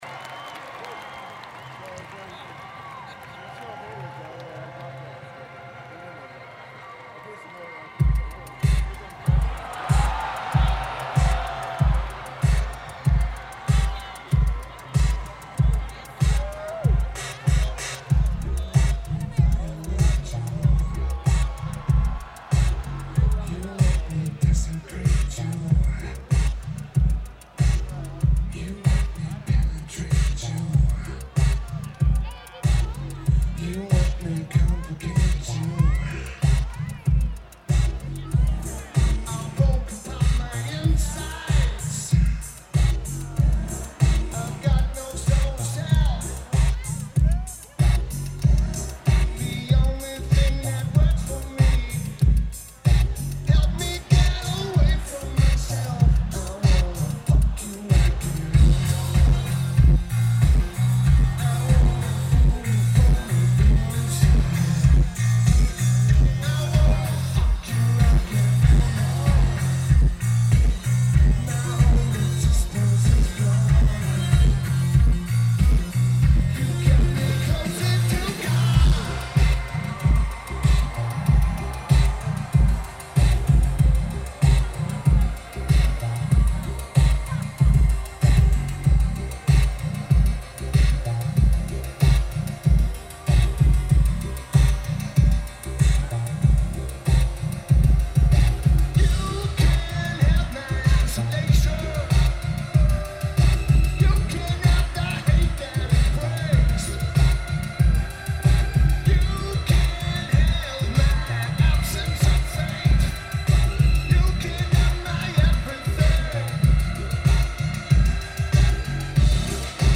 Outside Lands Festival